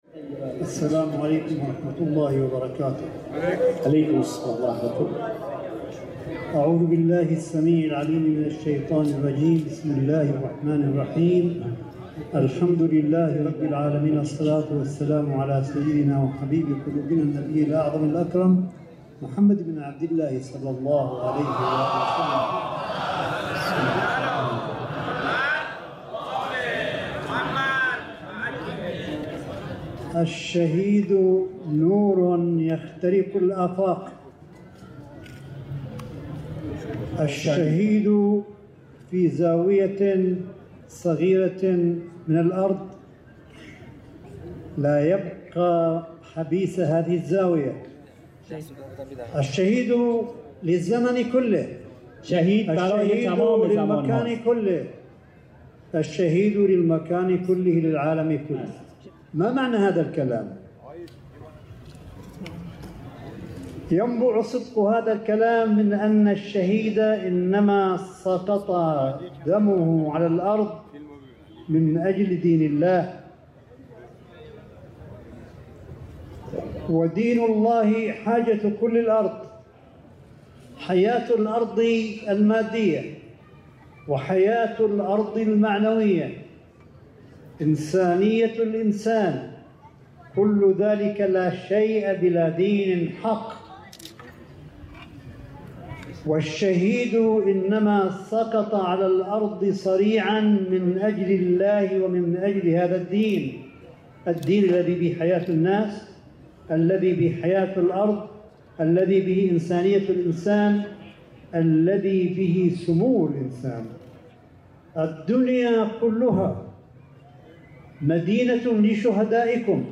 صوت : كلمة آية الله قاسم في لقاء بالكادر الإداري والتعليمي بجامعة قم المقدسة